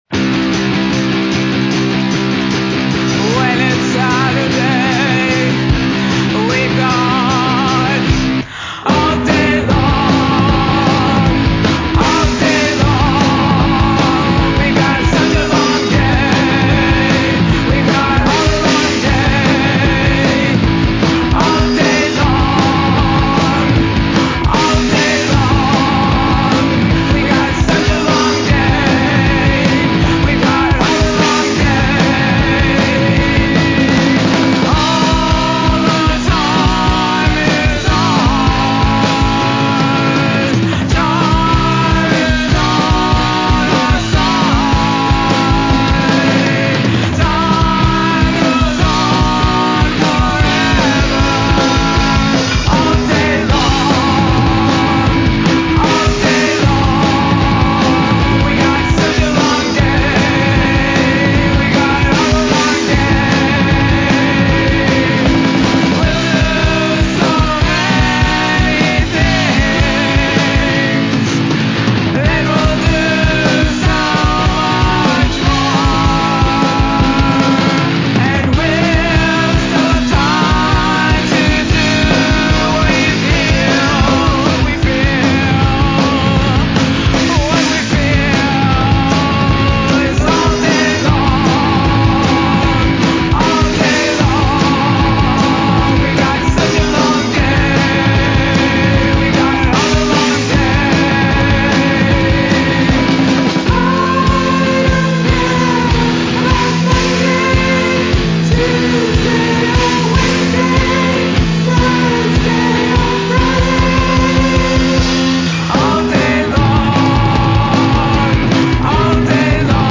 Guitar and Lead Vocal
Drums, Percussion, and Backing Vocal